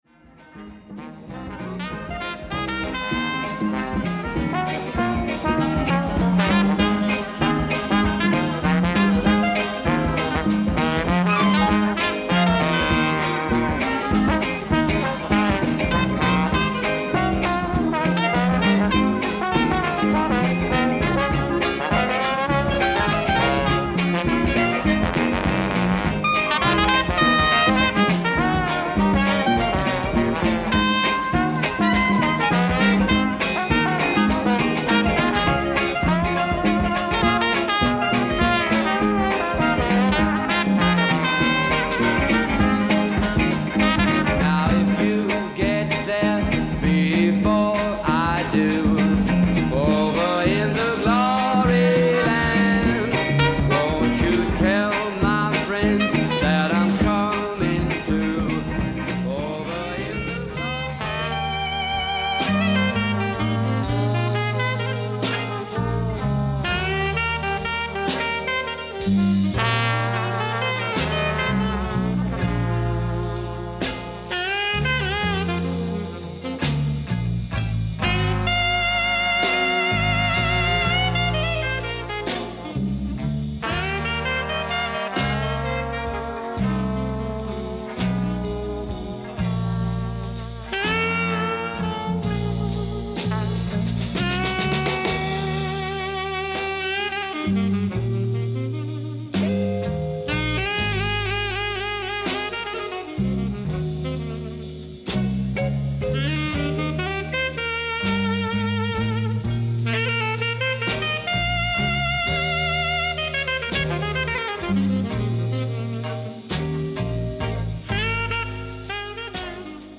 clarinet and alto sax
short excerpt from the concert in East Berlin